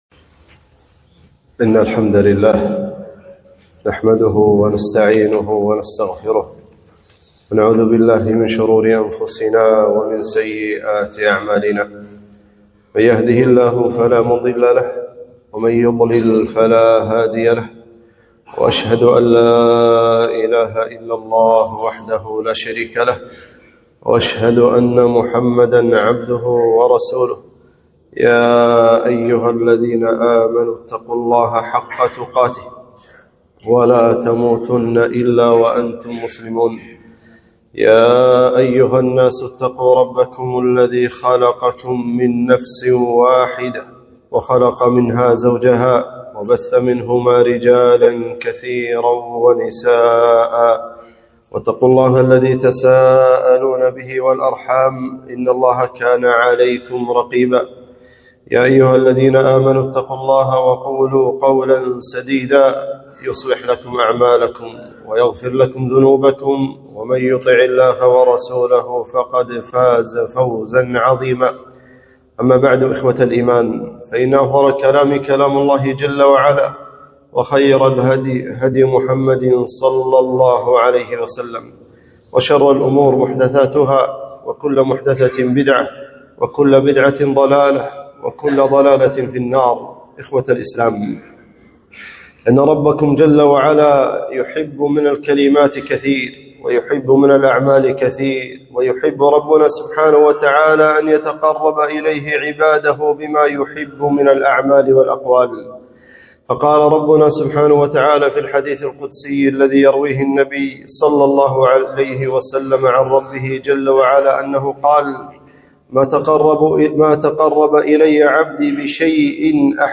خطبة - لا حول ولا قوة إلا بالله